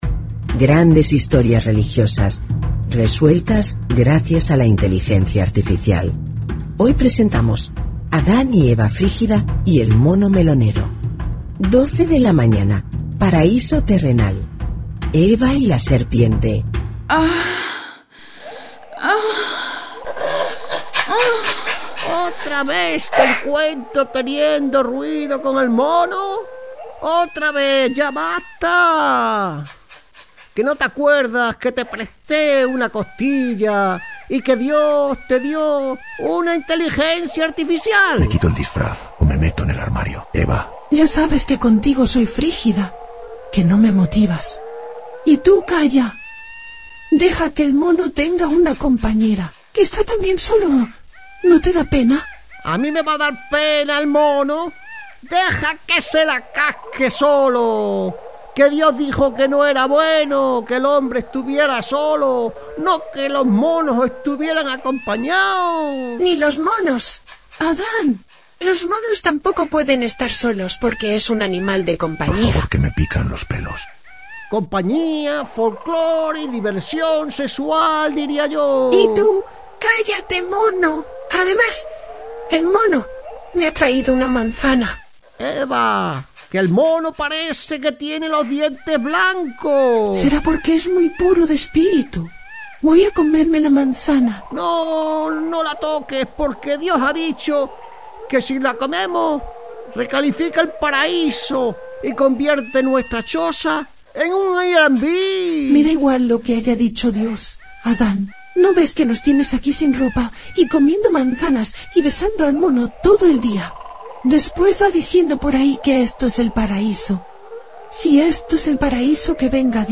guiones de humor para podcast